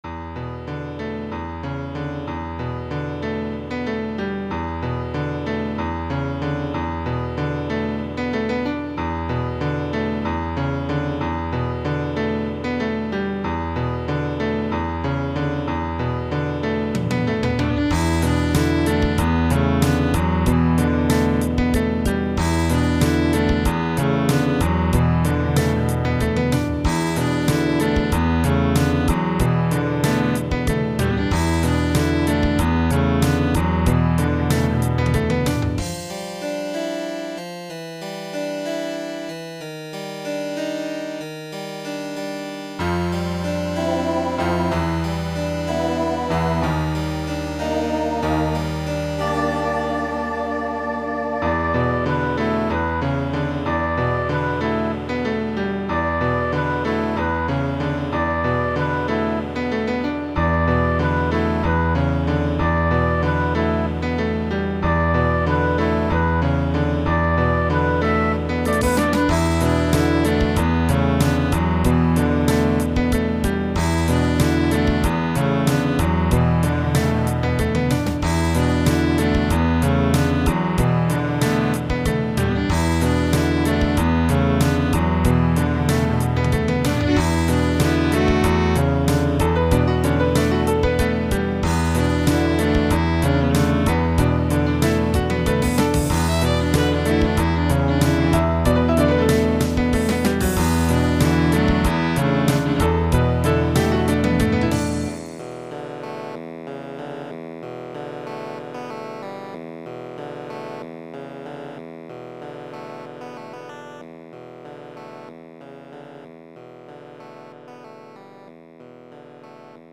A piece in 7/8, from March 17, 1999.